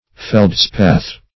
Feldspar \Feld"spar`\, Feldspath \Feld"spath`\, n. [G.